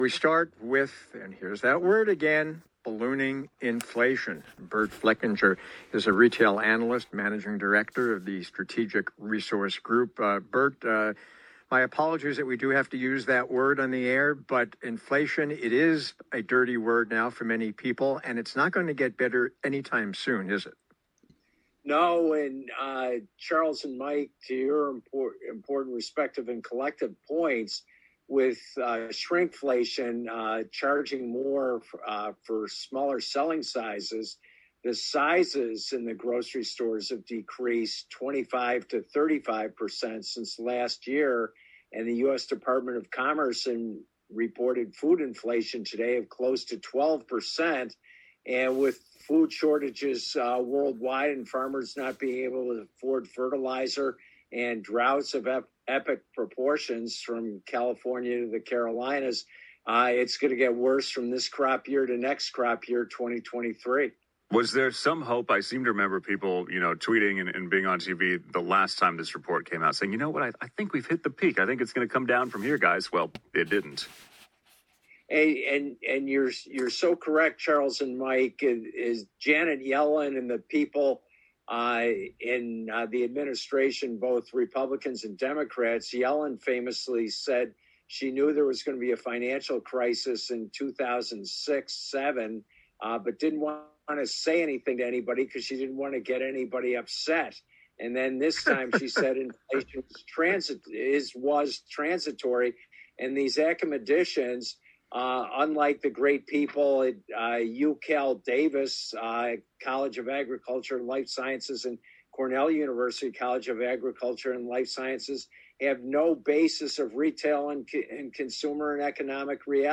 live radio broadcast on California’s #1 24-Hours News Radio Station KNX-CBS